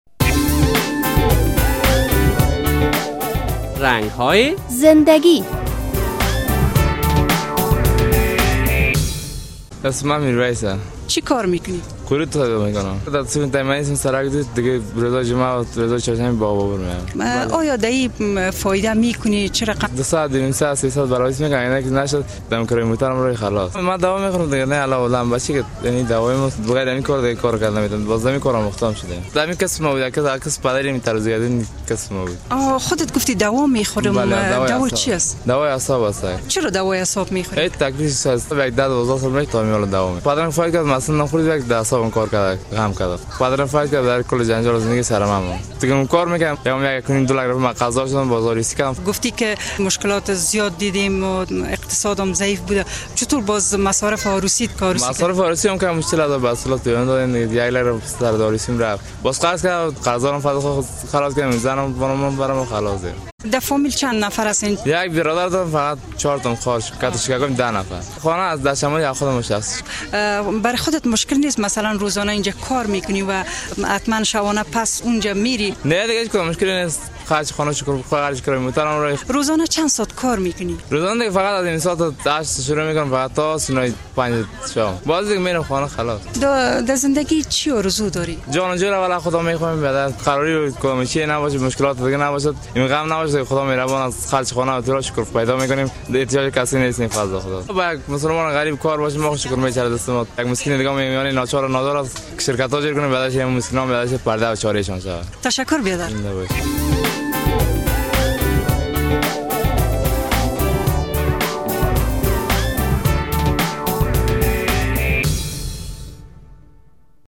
جوانان افغان در شهر کابل برای امرار معیشت زندگی خود به کار های مختلف مصروف هستند. در این برنامه با یک تن از قروت فروشان مصاحبه کرده ایم.